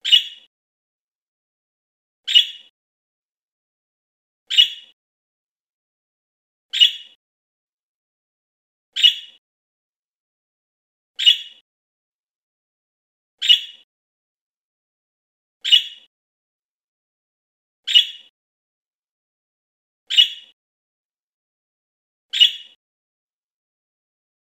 Wavy Parrot - The Wavy Parrot's Cry 31435
• Category: Wavy parrots